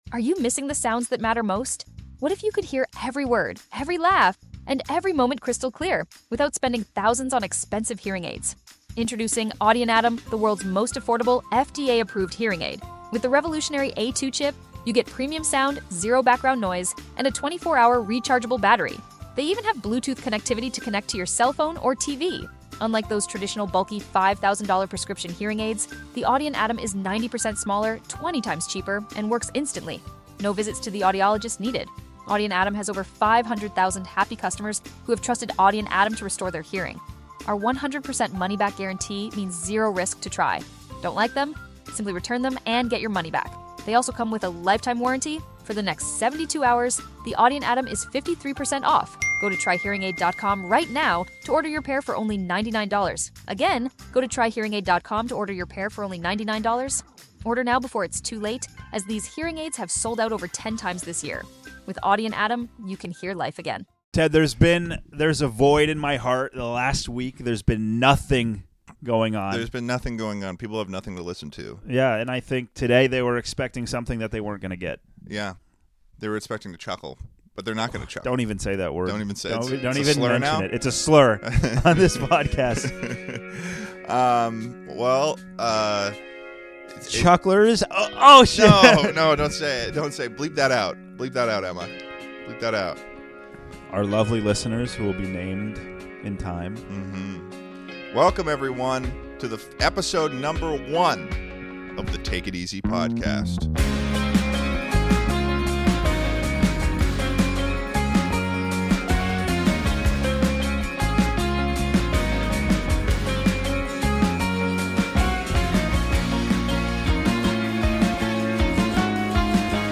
The sun rises once more in the world of podcasting, as two longtime friends prepare to, indefinetly, Take It Easy.